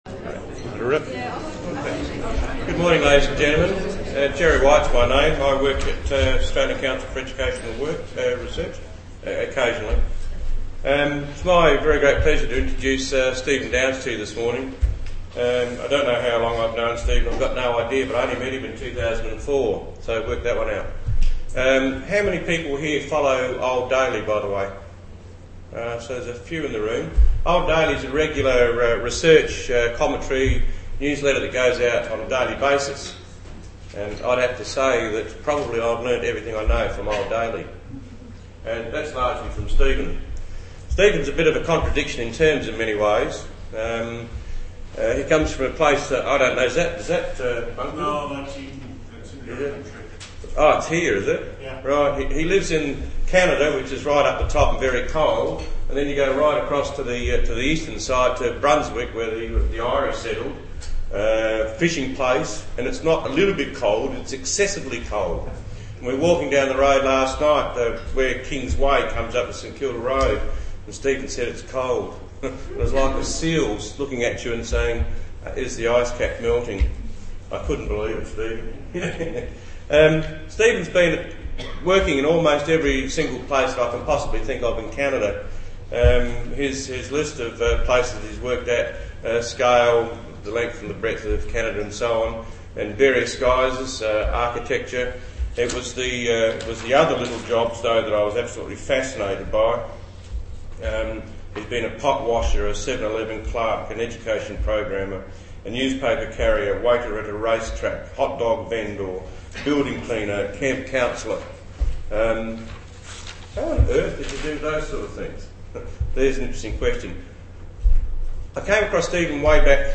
Workshop - the audio is roughly two hours - on the design and structure of a connectivist course. The real changes in eLearning will not come from the area of technological innovation but rather pedagogical innovation.
ACER Melbourne 2009, Australian Council for Educational Research, Melbourne, Australia, Seminar, Apr 06, 2009.